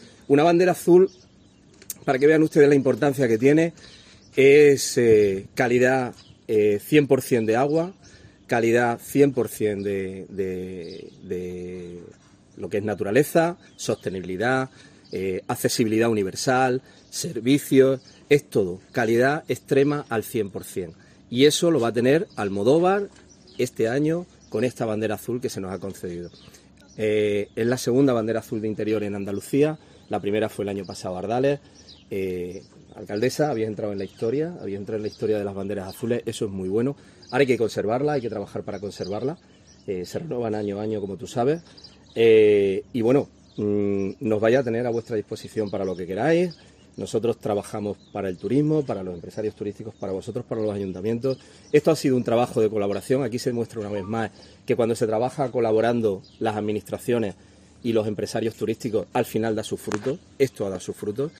En declaraciones a los periodistas, el delegado territorial de Turismo en Córdoba, Ángel Pimentel, que ha visitado este jueves el municipio invitado por su alcaldesa, María Sierra Luque, ha recalcado el "muy buen trabajo" realizado por la localidad y la Consejería de Turismo, recalcando que la Bandera Azul reconoce "la calidad del agua, naturaleza, sostenibilidad, accesibilidad universal y servicios" que tienen esta playa de interior.